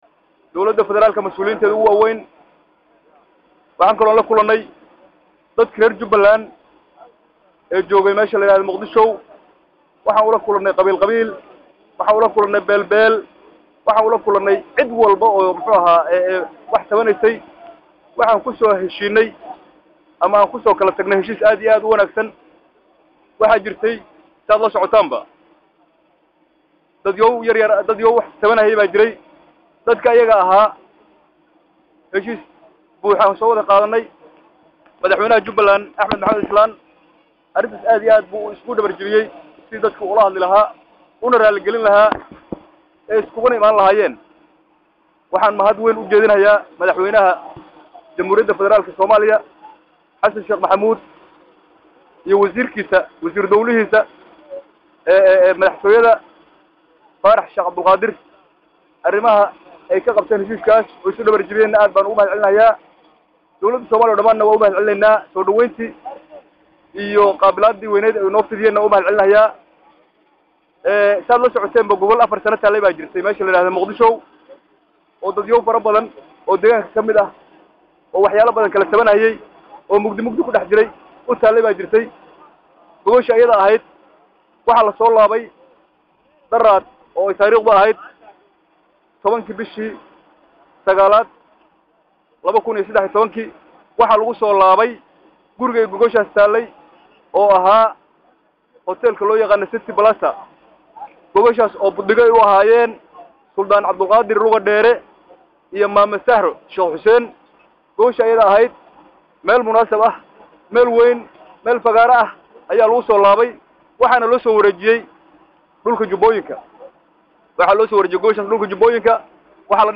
Markii ay soo caga dhigatay diyaradii siday wafdiga , waxaa qeebta nasashada Airapootka kula hadlay saxafada Madaxweyne ku xigeenka Jubba Cabdulaahi Sheekh Ismaaciil Farataag asagoo sheegay in ay goordhow bilaabi doonaan fidinta Gogol Nabadeed.